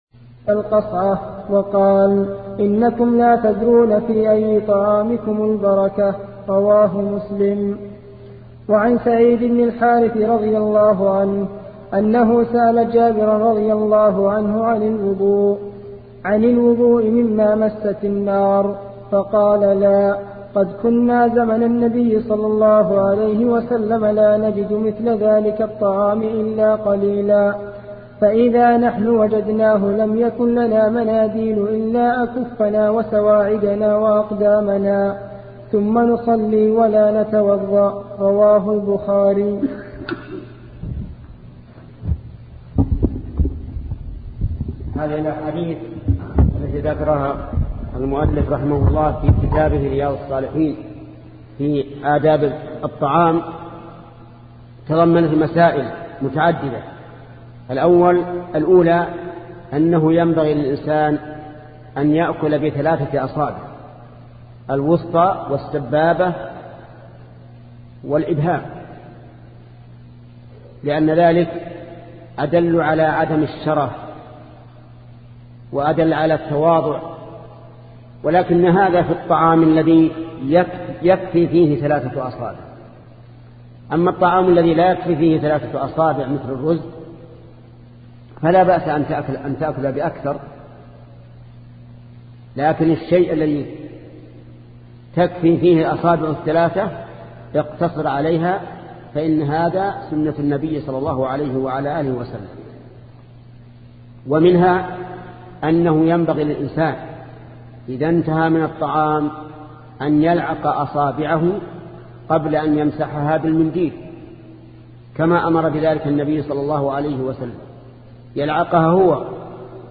سلسلة مجموعة محاضرات شرح رياض الصالحين لشيخ محمد بن صالح العثيمين رحمة الله تعالى